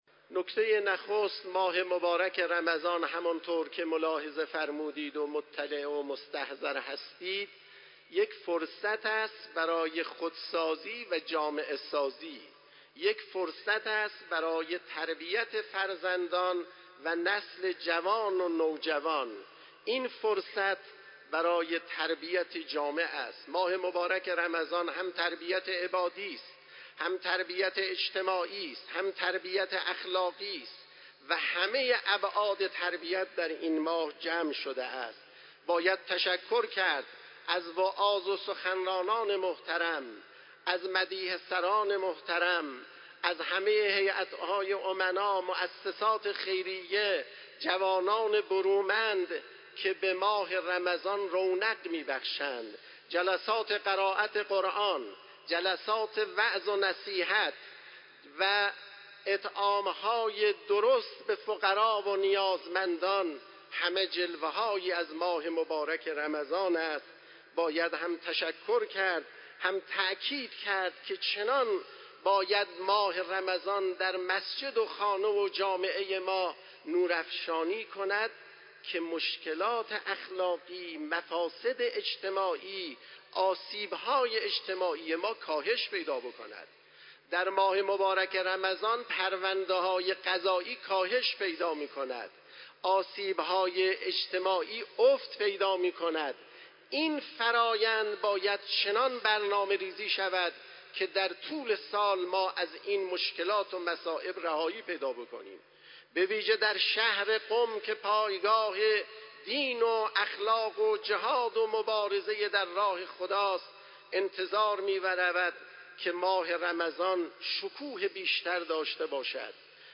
به گزارش خبرنگار سیاسی خبرگزاری رسا، آیت الله علیرضا اعرافی امروز در خطبه های عبادی و سیاسی نماز جمعه قم که در مصلای قدس برگزار شد، با اشاره به فرصت بی نظیر ماه مبارک رمضان برای ترقی معنوی انسان گفت: در ماه مبارک رمضان حتی نفس ها و خواب انسان روزه دار به عنوان تسبیح حساب می شود.